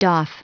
Prononciation du mot doff en anglais (fichier audio)
Prononciation du mot : doff